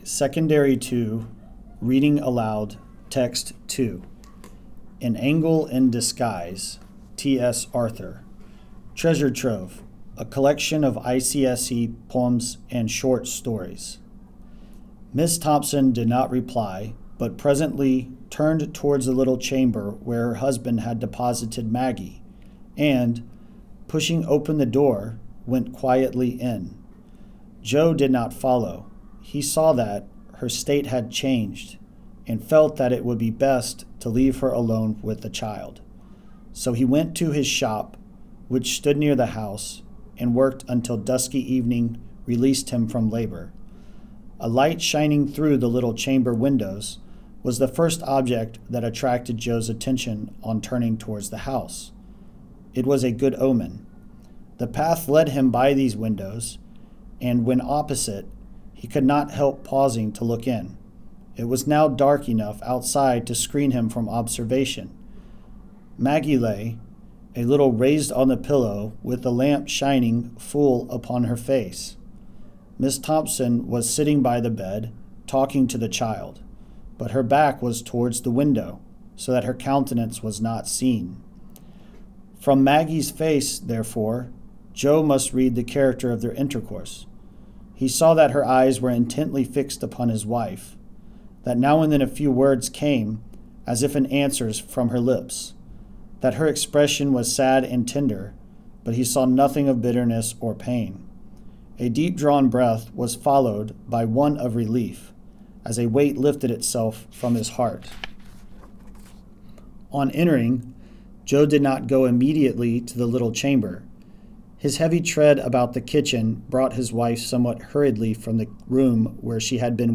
Reading Aloud
แบบฝึกการอ่าน และการออกเสียงภาษาอังกฤษ ปีการศึกษา 2568